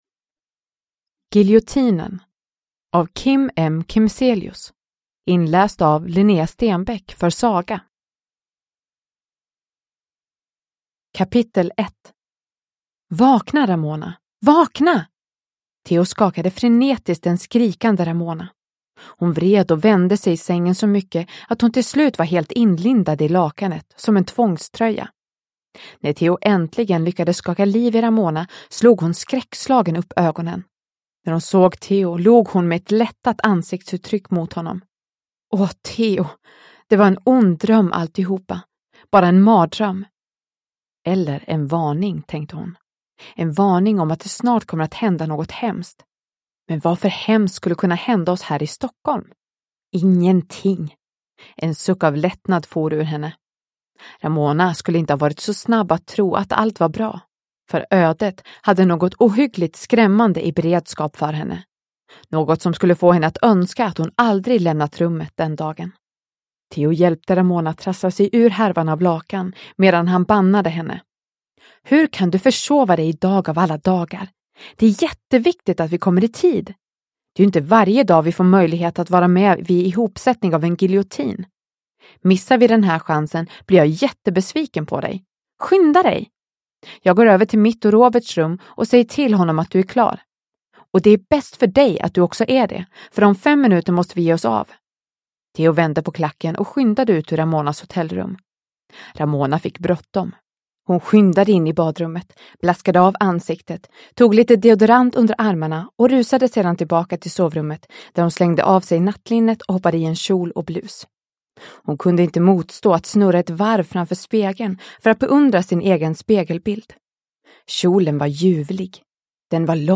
Giljotinen / Ljudbok